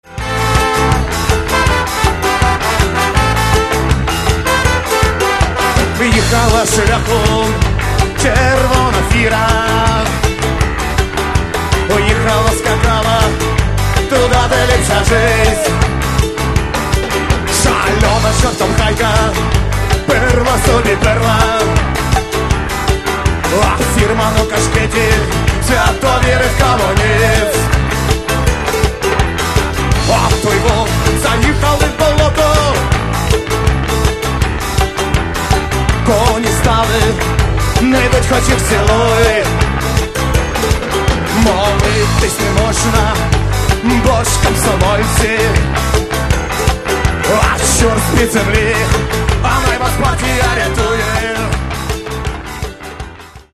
Еще немного громче и – до свидания!